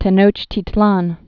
(tĕ-nōchtē-tlän)